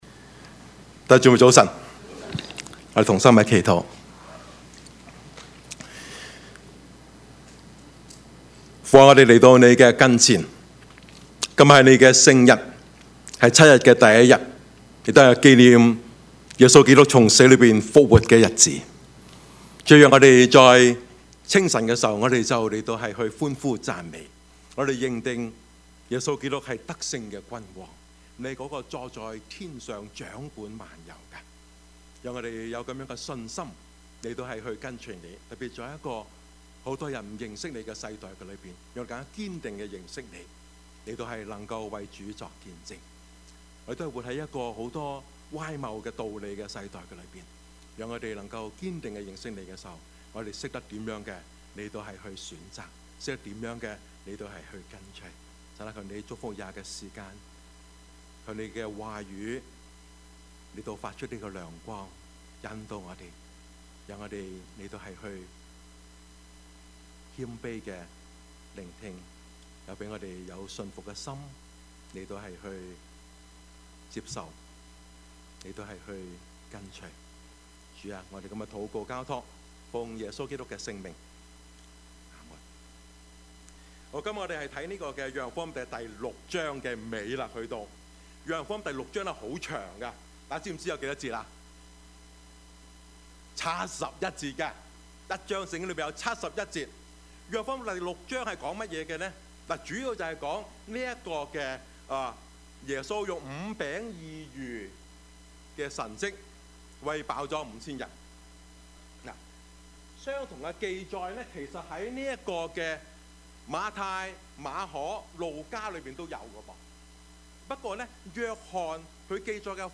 Service Type: 主日崇拜
Topics: 主日證道 « 生命的福音 吃教?信主?